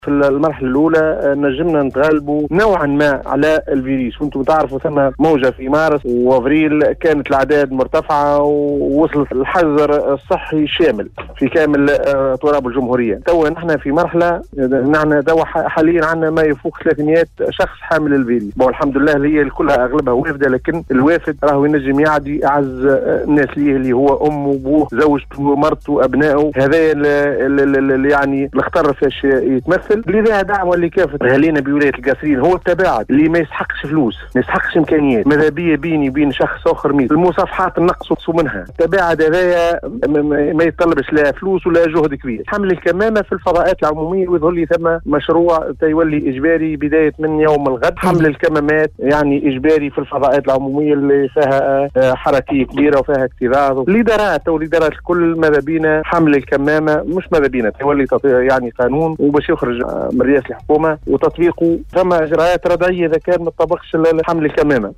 أكّد المدير الجهوي للصحة بالقصرين عبد الغني الشّعباني خلال تدّخله صباح اليوم الأحد 9 أوت 2020 ببرنامج بونجور ويكاند بإذاعة السيليوم أف أم أنّه سيتم بداية الاسبوع المقبل إصدار  نص قانوني يقضي بإجبارية ارتداء الكمامات داخل الفضاءات المغلقة و يعاقب كلّ من لا يلتزم بذلك .